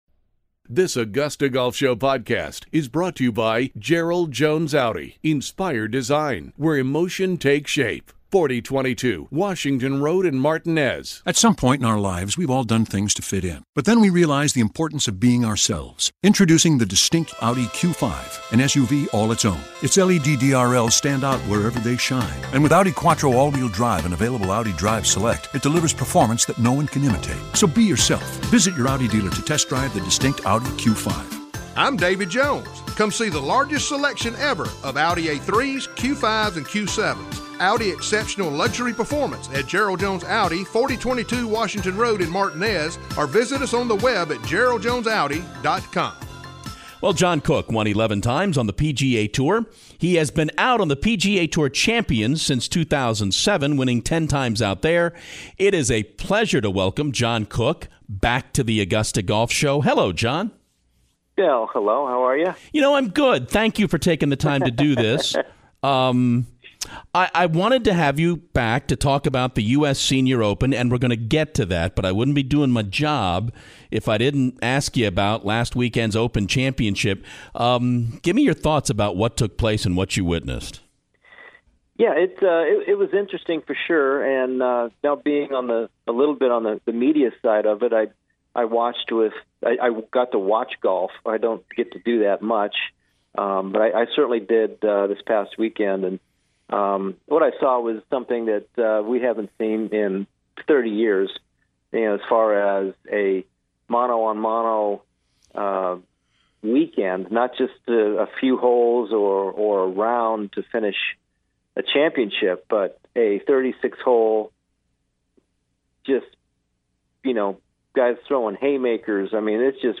John Cook: The Augusta Golf Show Interview